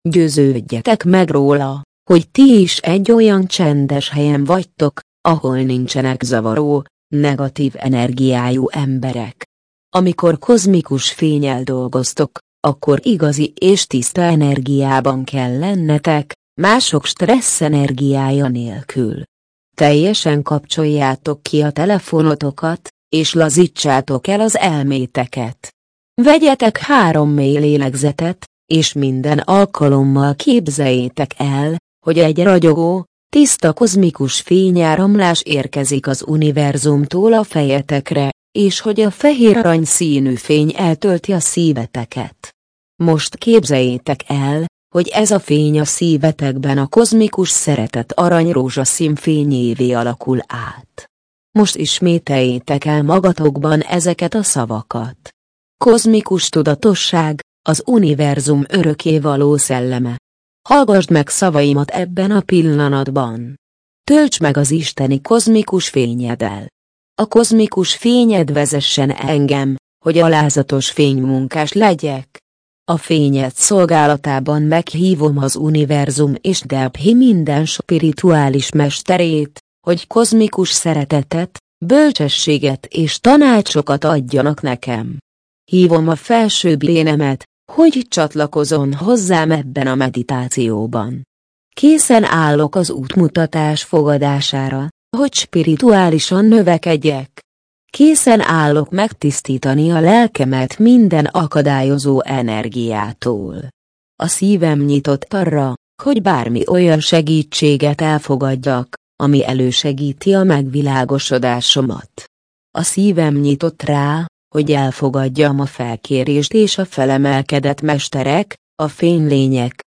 Meditációk
Magyar nyelvű gépi felolvasás